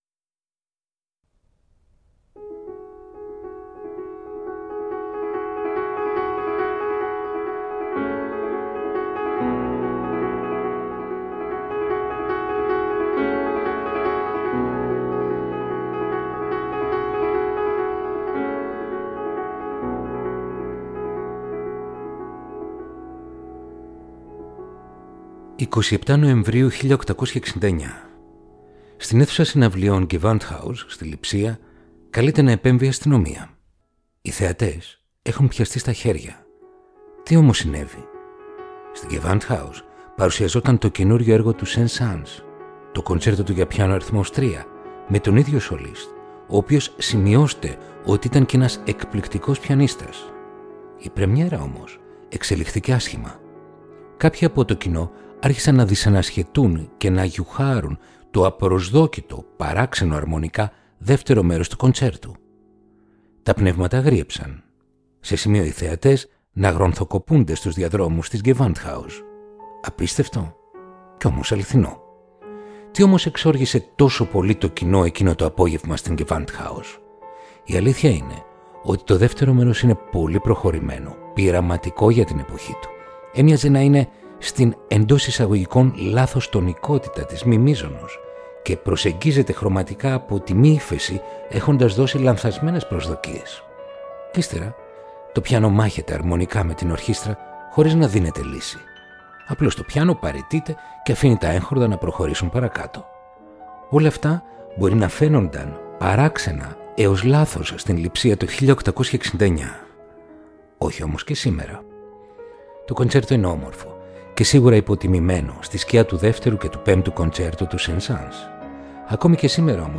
Ρομαντικά κοντσέρτα για πιάνο – Επεισόδιο 14ο